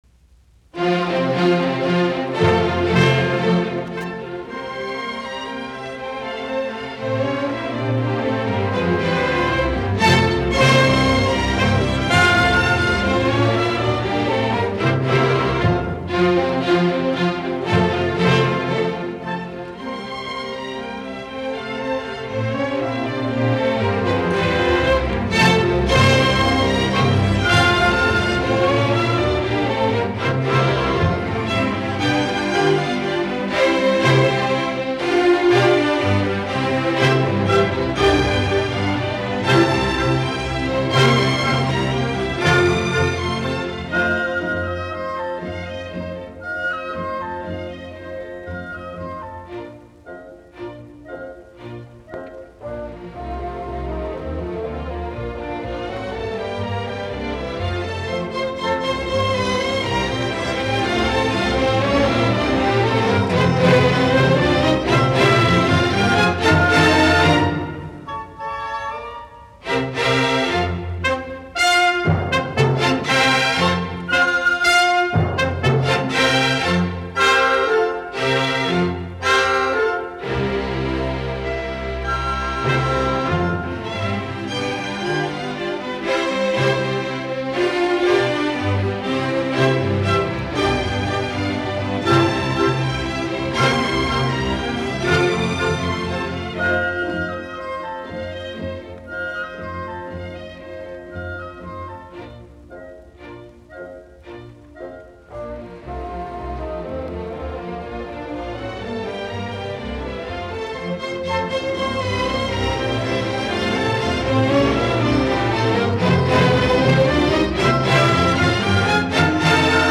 Allegretto scherzando